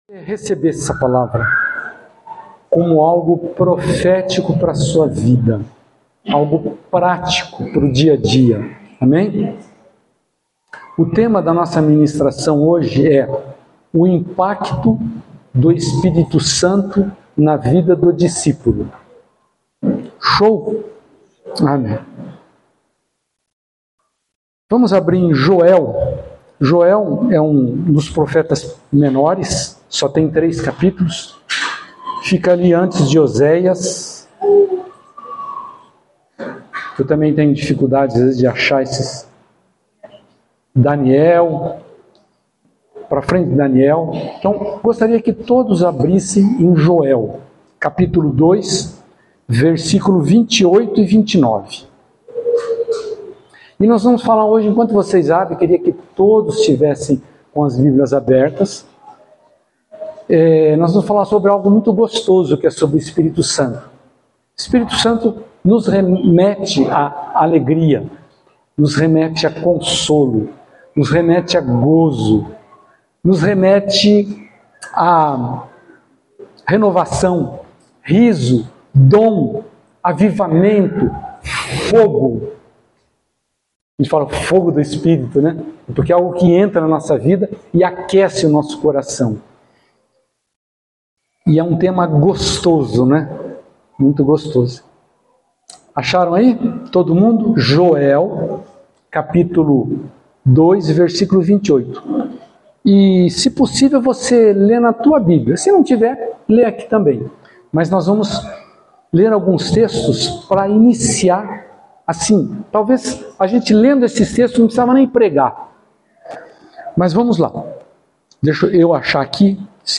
no culto do dia 01/12/2024 – Tema: O impacto do Espírito Santo na vida do discípulo
Palavras ministradas